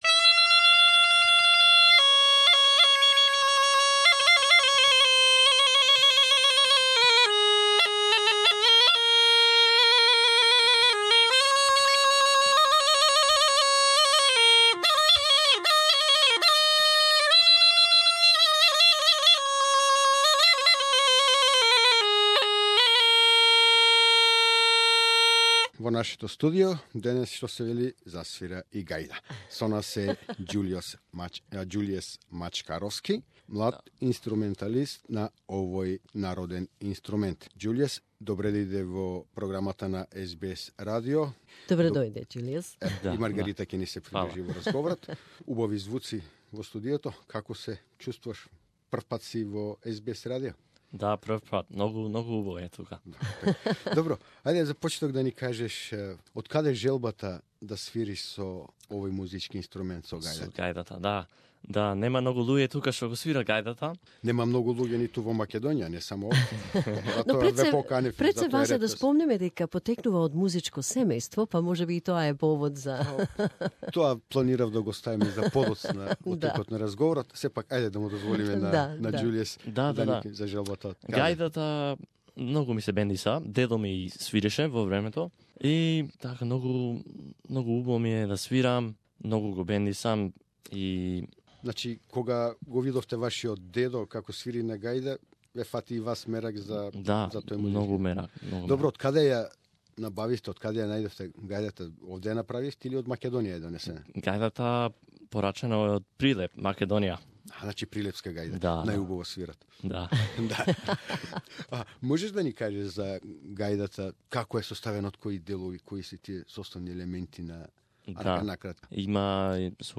Macedonian gajda (bagpipes)